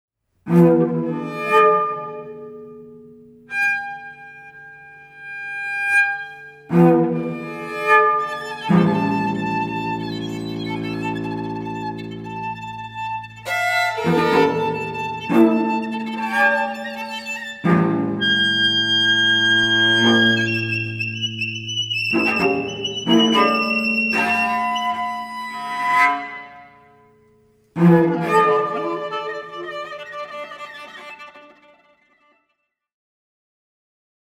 at bernard haitink hall of the conservatory of amsterdam
clarinet and shakuhachi
cello